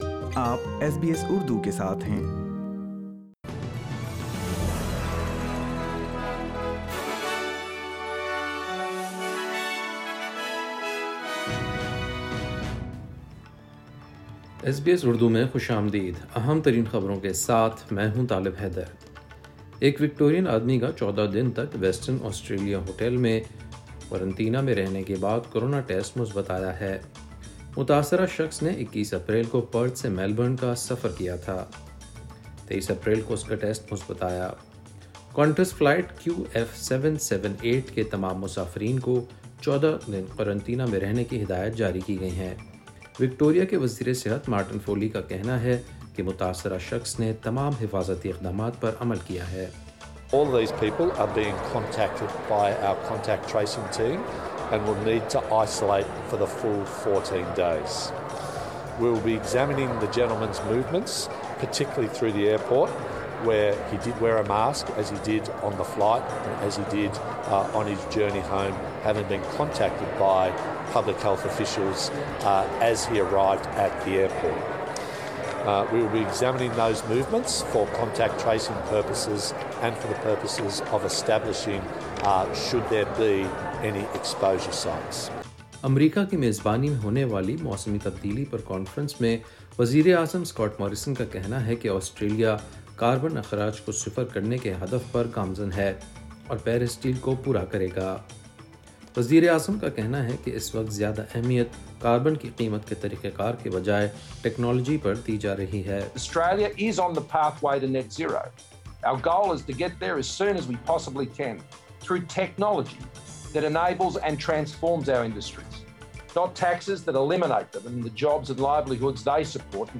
SBS Urdu News 23 April 2021